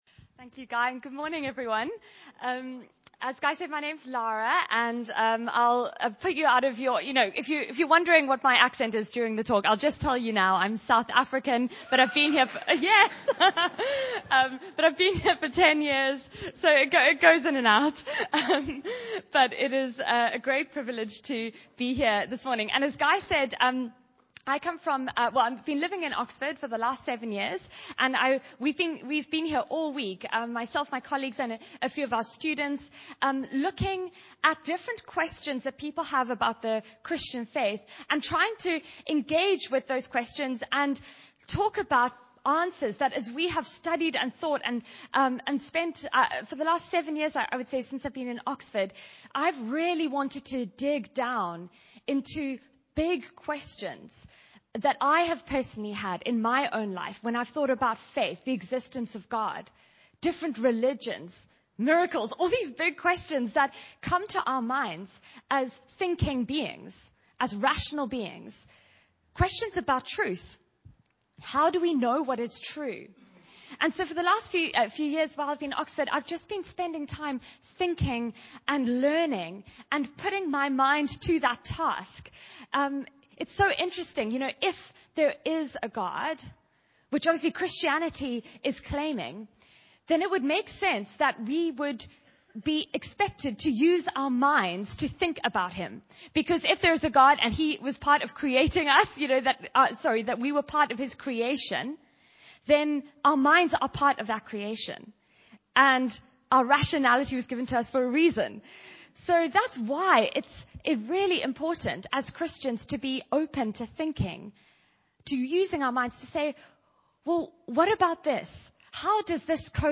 John 20:24-25 Service Type: Sunday Meeting Bible Text